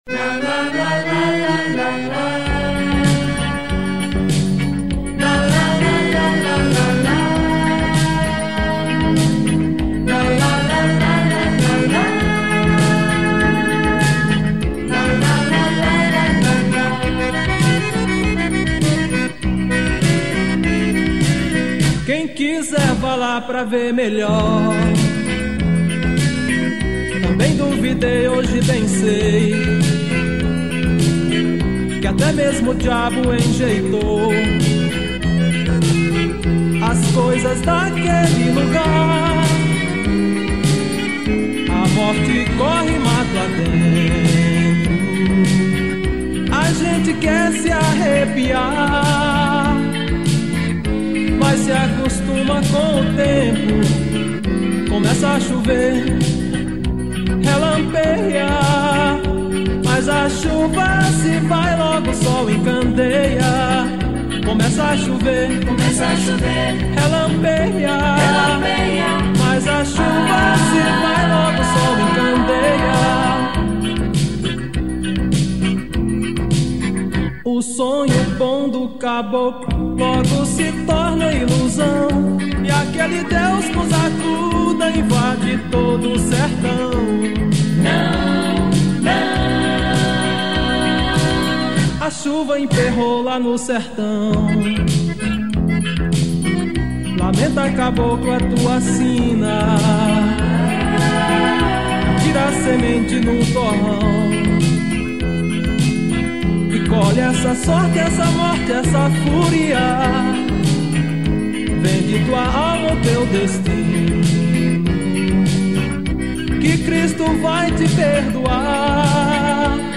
1867   04:10:00   Faixa:     Forró